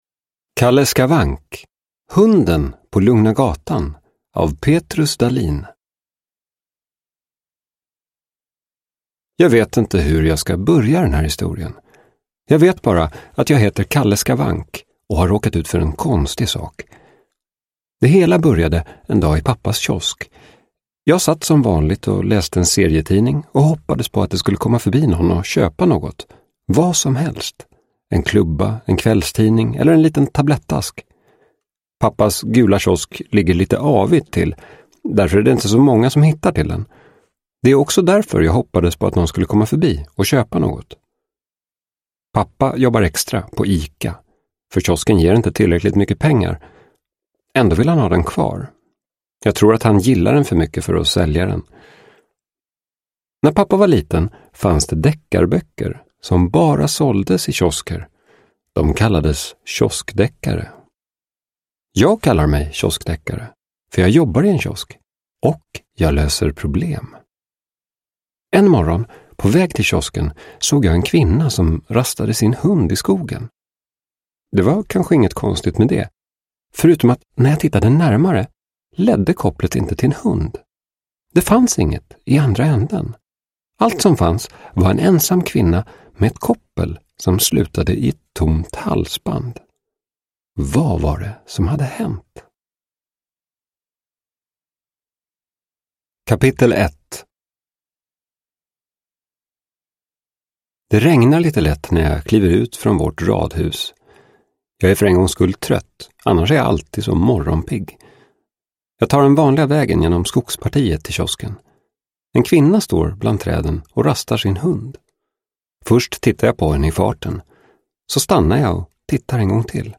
Hunden på Lugna gatan – Ljudbok – Laddas ner